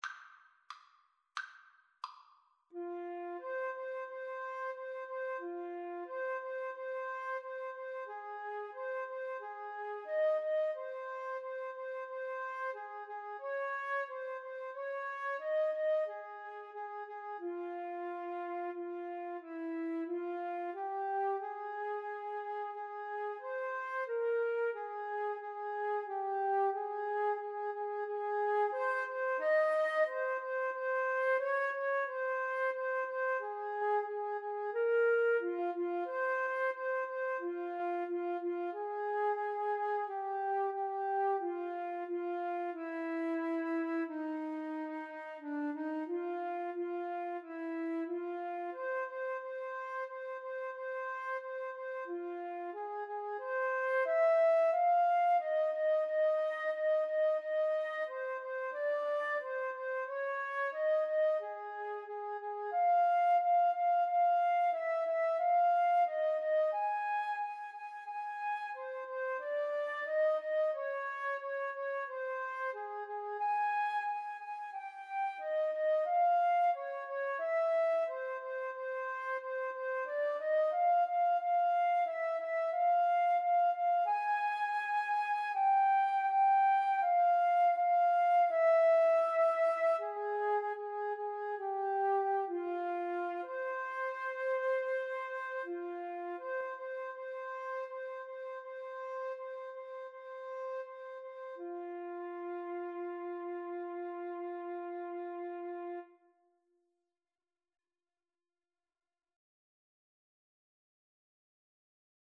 Flute 1Flute 2
2/4 (View more 2/4 Music)
=90 Allegretto, ma un poco lento
Classical (View more Classical Flute Duet Music)